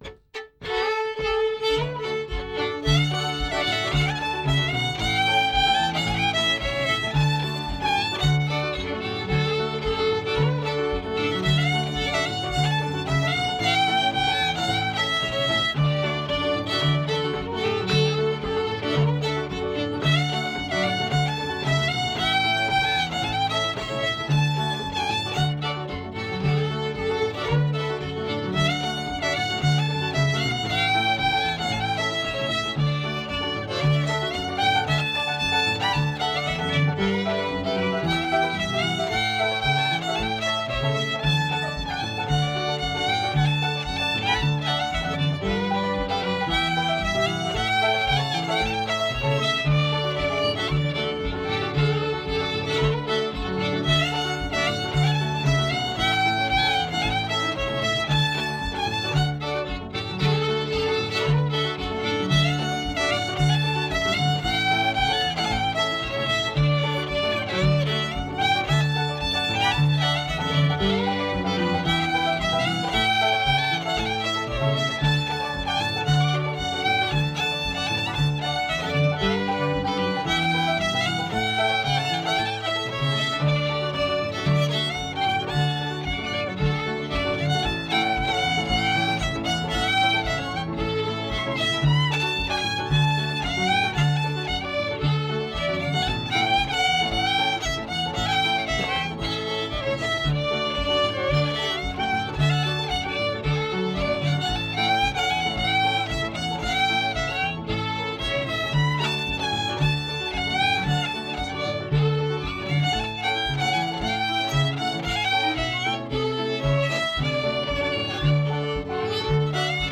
pno